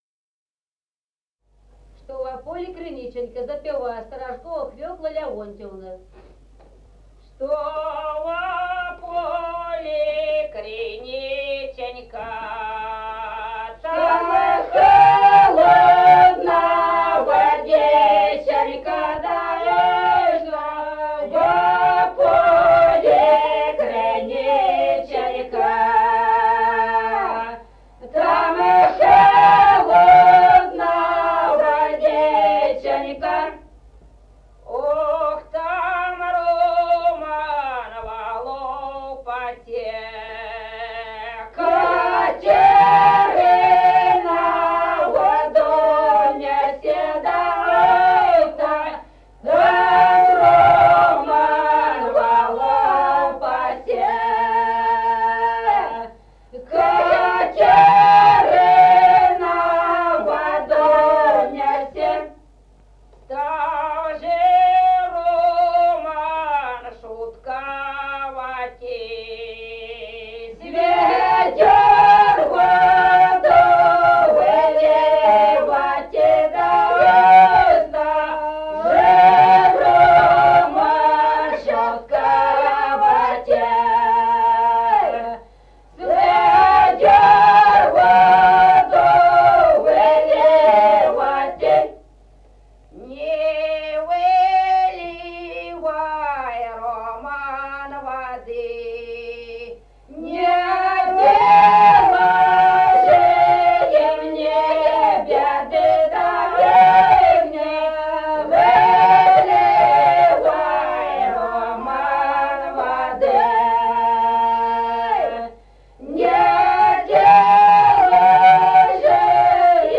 Народные песни Стародубского района «Что во поле криниченька», лирическая.
запев
подголосник
с. Остроглядово.